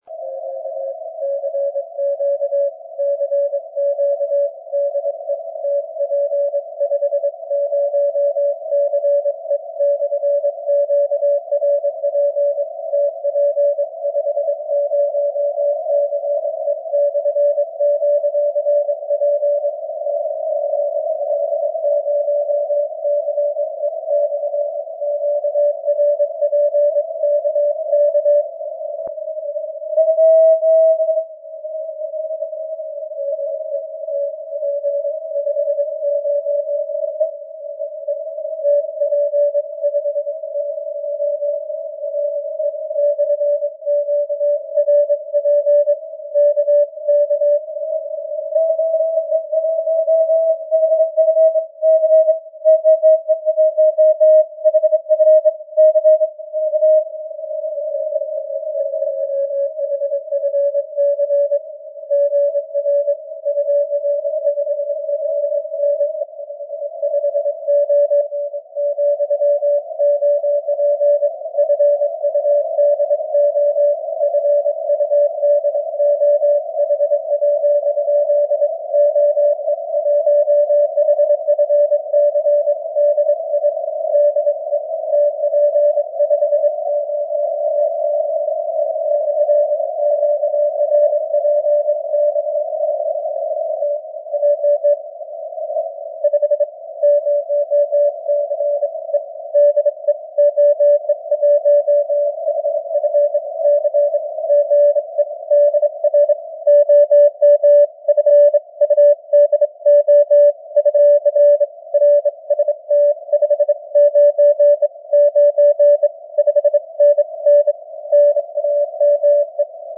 Временами сигнал вылезал на чистые 559, было легко и приятно слушать.
Диапазон у меня сейчас очень шумный, сигналы вытаскивал "коунтером" и заужением полосы временами до 100 Гц.
Но слышно неплохо обоих.